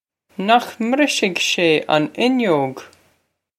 Pronunciation for how to say
Nokh mbrish-hig shay on in-yoh-g?
This is an approximate phonetic pronunciation of the phrase.